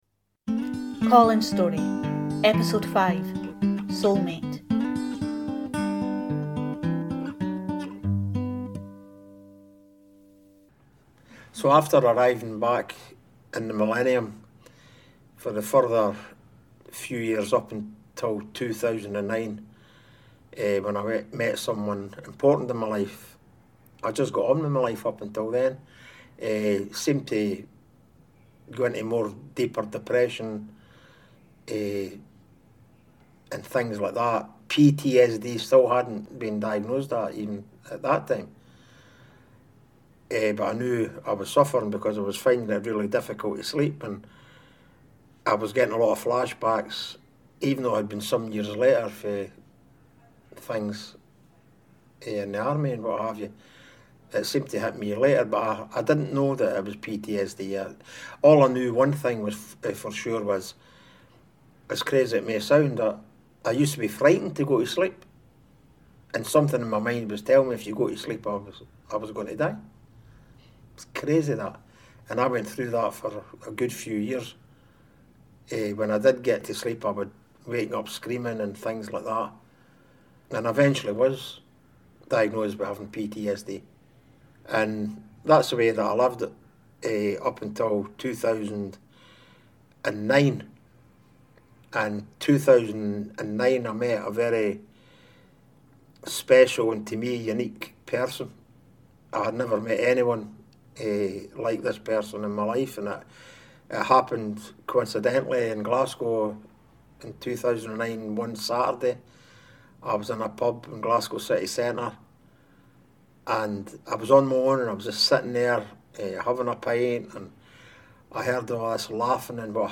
He tells his story with great candour and often, great humour.